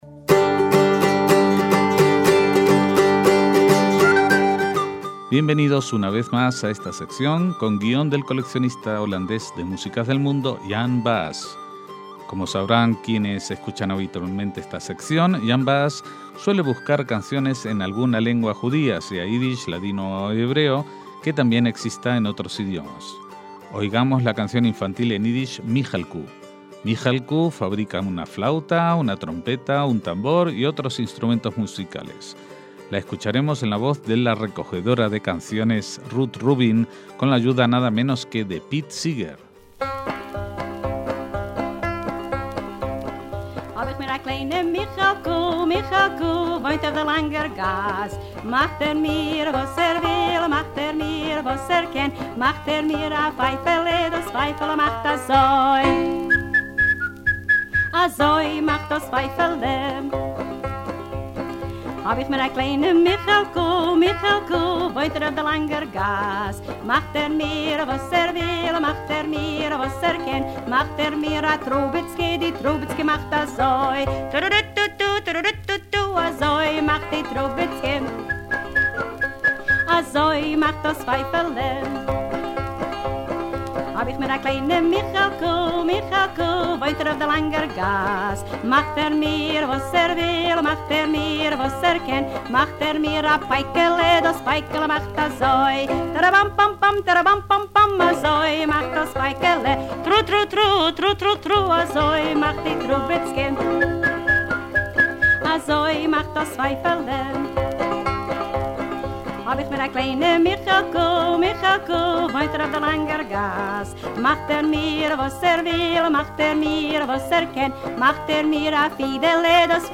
En esta ocasión escucharemos canciones en varios idiomas, incluidos el ídish, el holandés y el inglés, en las que se hacen referencias a los músicos y sus instrumentos.